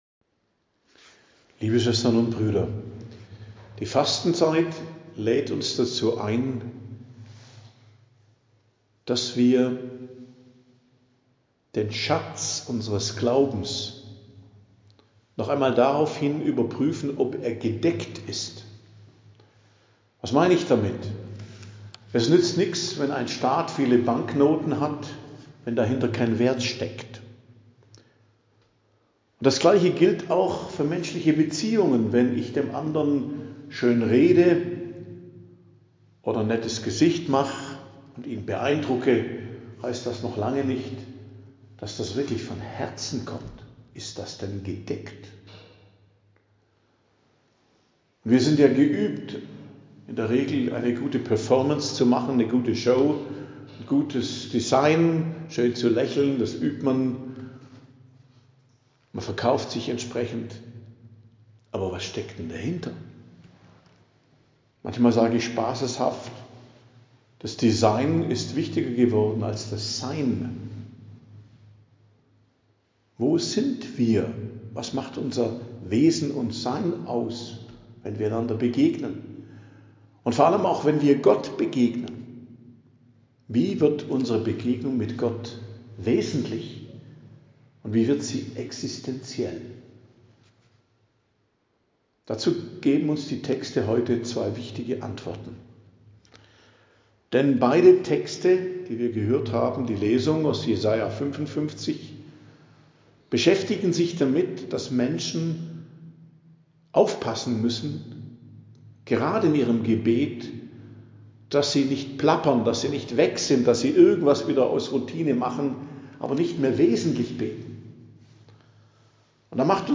Predigt am Dienstag der 1. Woche der Fastenzeit, 11.03.2025 ~ Geistliches Zentrum Kloster Heiligkreuztal Podcast